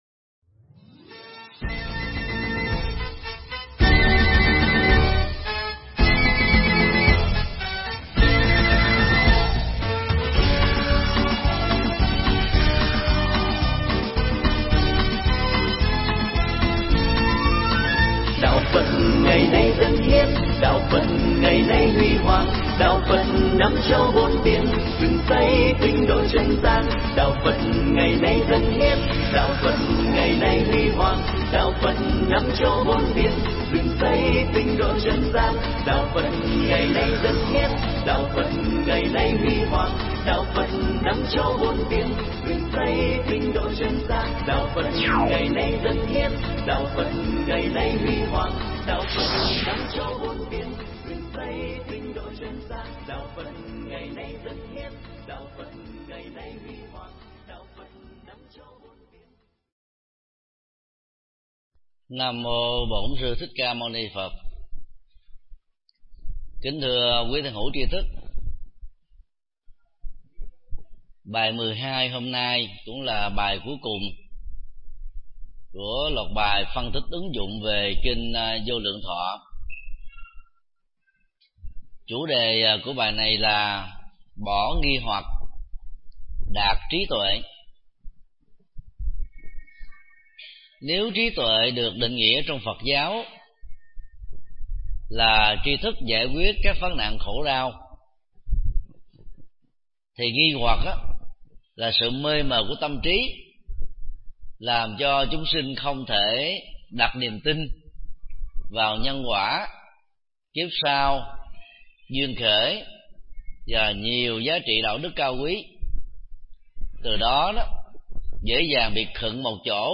Tải mp3 Bài giảng Kinh Vô Lượng Thọ 12 – Bỏ Nghi Hoặc Đạt Trí Tuệ do thầy Thích Nhật Từ giảng tại chùa Xá Lợi 27 tháng 01 năm 2013